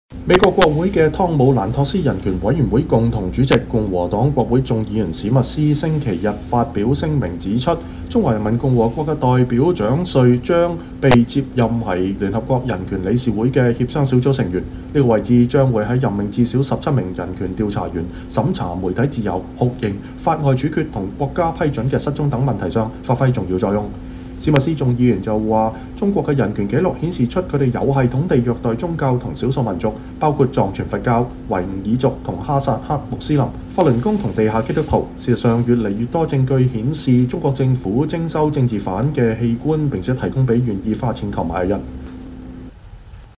美國國會眾議員史密斯接受美國之音專訪談中國人權問題(2016年4月14日)